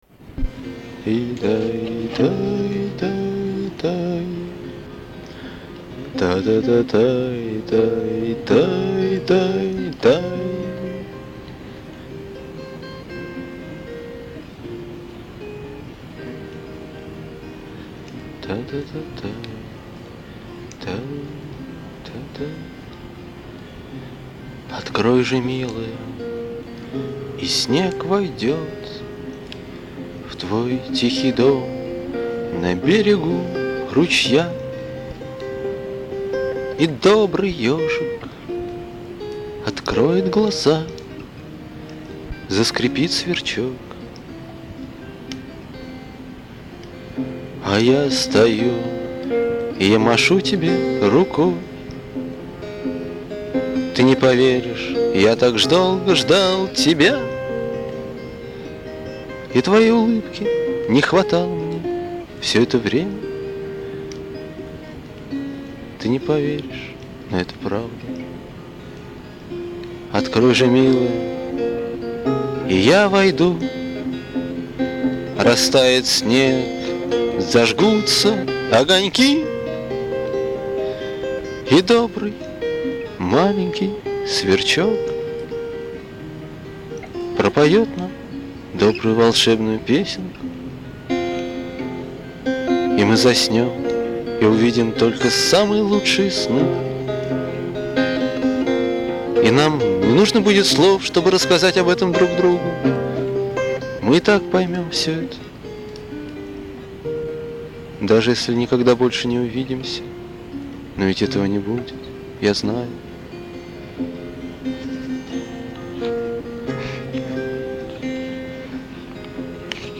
вокал
бэк-вокал
гитара     Обложка